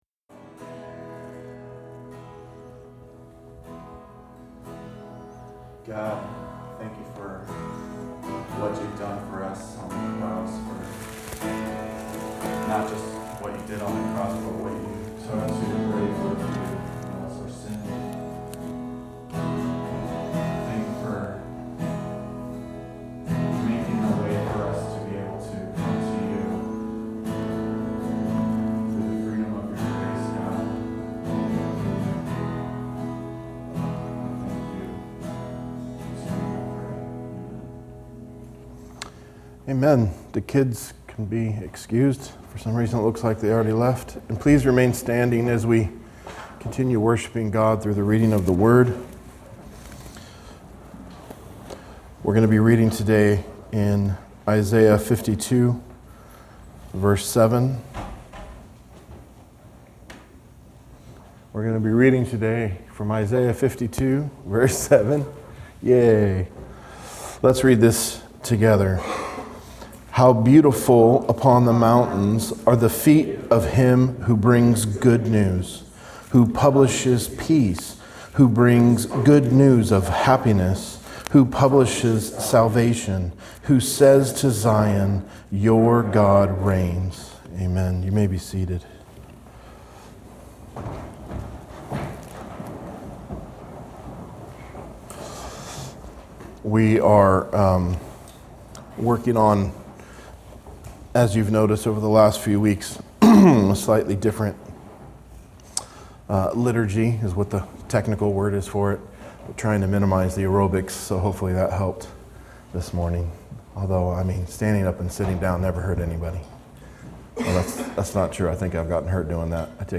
Expository teaching of Matthew 27:27-56